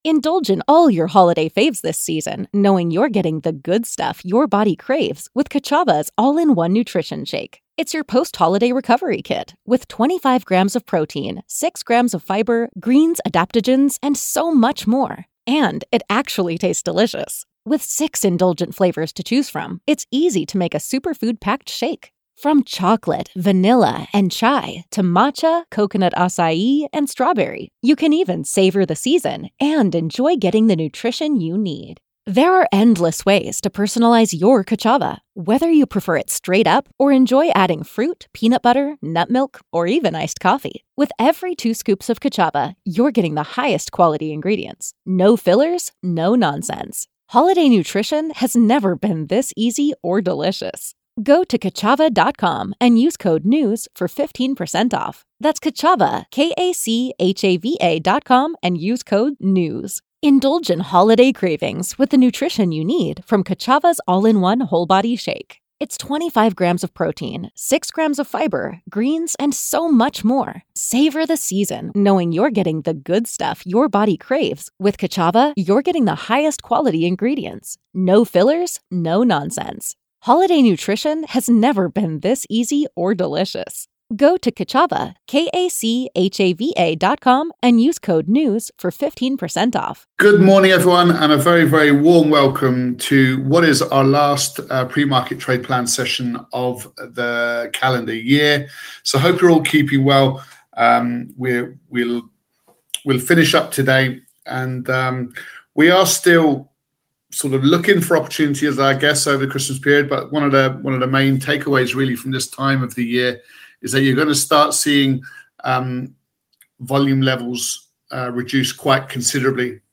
| Pre-Market Trade Plan Live | 22nd December 2025 21:47 Play Pause 3d ago 21:47 Play Pause Play later Play later Lists Like Liked 21:47 This daily podcast is broadcast live to our traders at 7:30 a.m. (UK Time) every morning.